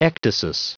Prononciation du mot ecdysis en anglais (fichier audio)
Prononciation du mot : ecdysis